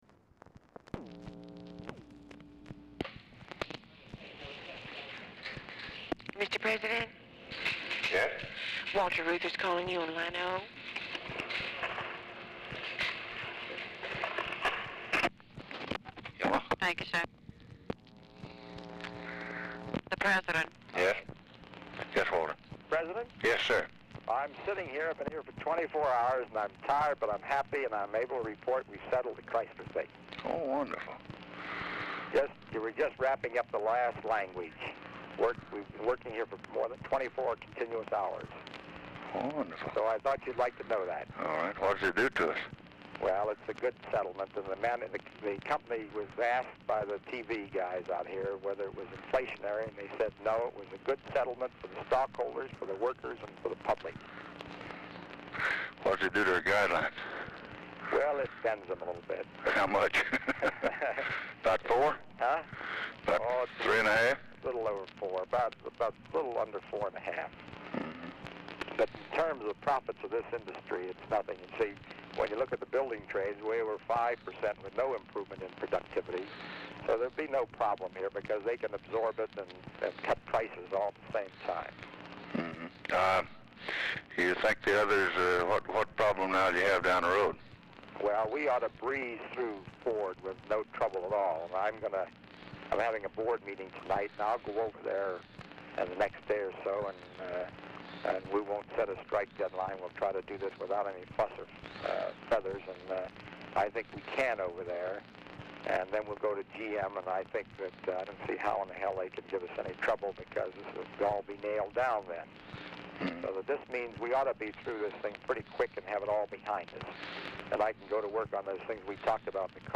Telephone conversation # 5541, sound recording, LBJ and WALTER REUTHER, 9/9/1964, 10:24AM | Discover LBJ
Format Dictation belt
Location Of Speaker 1 Oval Office or unknown location